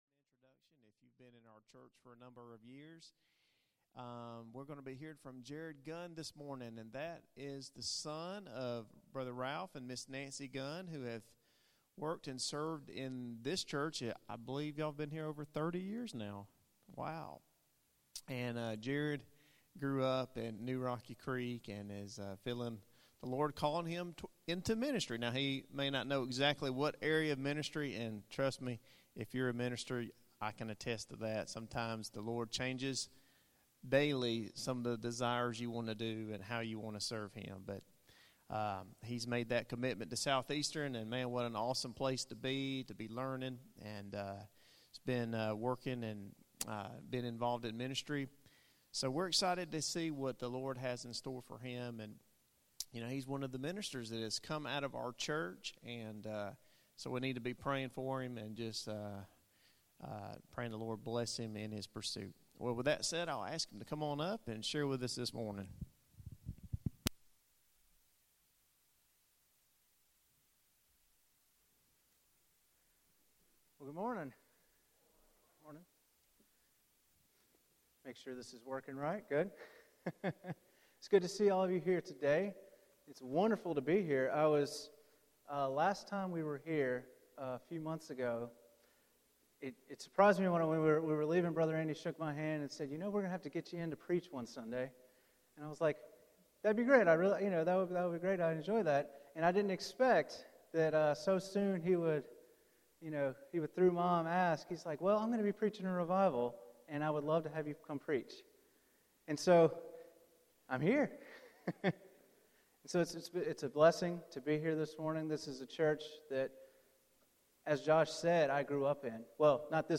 Sermons Archive | New Rocky Creek Baptist Church